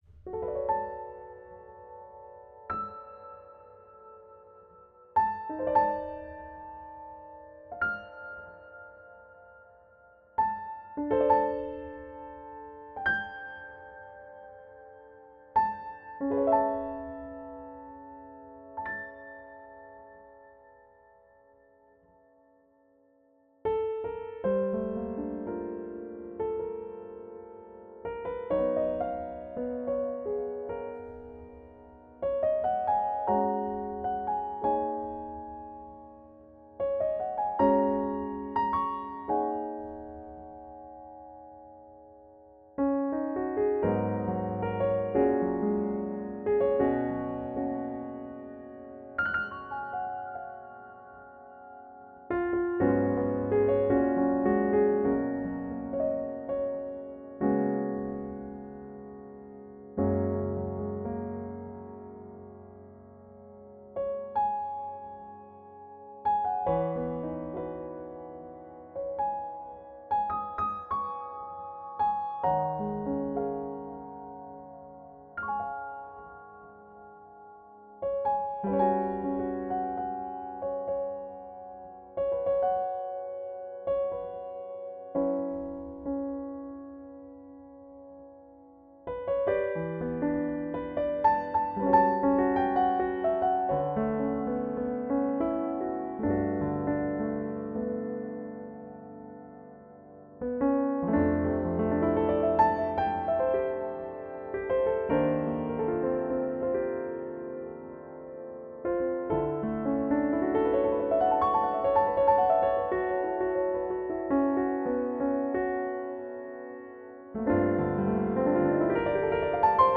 Q4：Piano
各音源よりNo.1プリセット（各製品の1番に当たるプリセット）のアコースティックピアノをロードして使用。ピアノそのものの音比較を公平に行うため、音源内蔵のリバーブをオフにし、4製品に共通のリバーブをDAW上でかけてある。
各製品から最高の状態を引き出すため、製品ごとにベロシティカーブをごくわずかに調整。 長めのサンプル中、前半は弱めのベロシティを中心に演奏。後半は中〜強ベロシティを交えた演奏となっており、解答の参考としてほしい。
マスターチャンネルではクリッピング防止のため、WAVESのL2をインサートして、0dBを超えない設定にてファイルを書き出している。L2上では音圧をあげるための設定は一切行っていない。
Piano-A
MI-New-WEB-Quiz-Piano_1.m4a